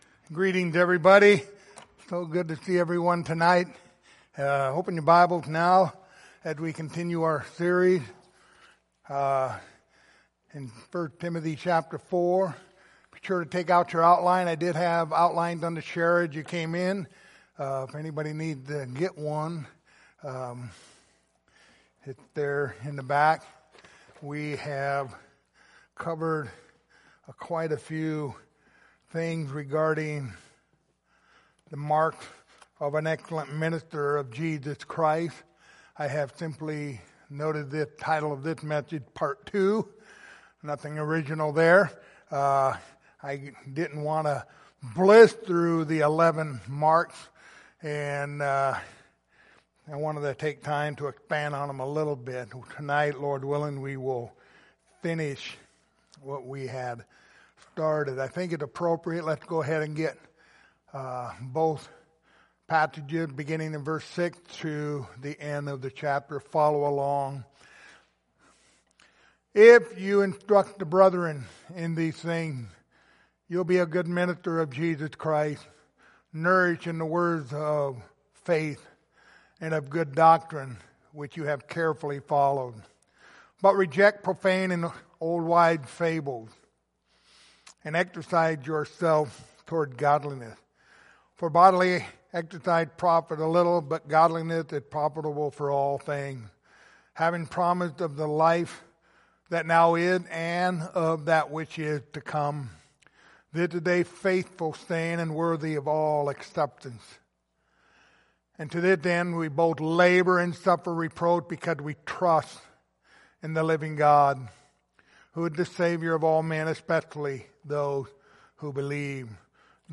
Passage: 1 Timothy 4:12-16 Service Type: Sunday Evening